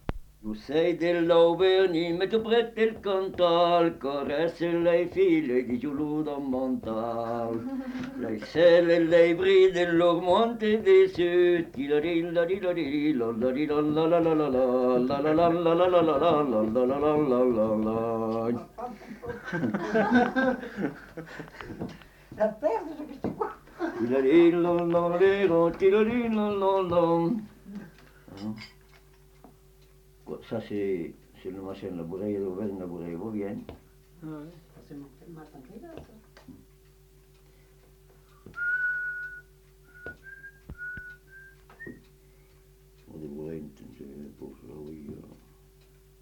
Aire culturelle : Viadène
Genre : chant
Effectif : 1
Type de voix : voix d'homme
Production du son : chanté ; fredonné
Danse : bourrée